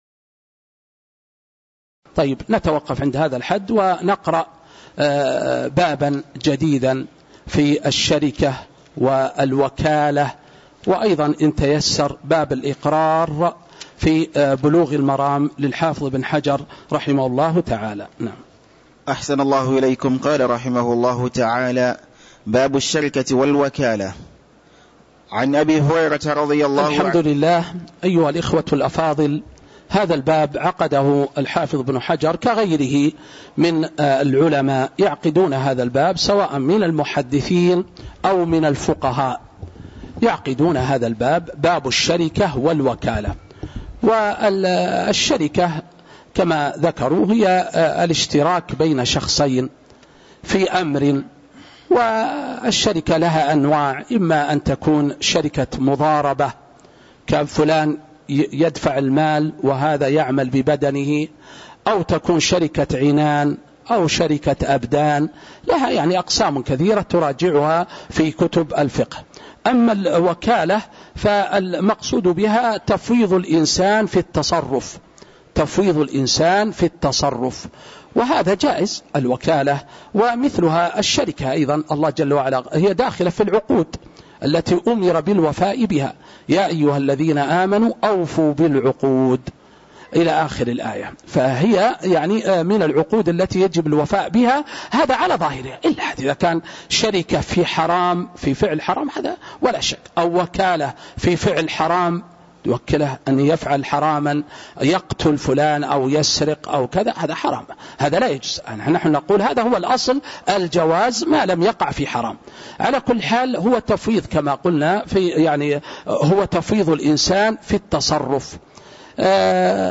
تاريخ النشر ٢٨ جمادى الأولى ١٤٤٦ هـ المكان: المسجد النبوي الشيخ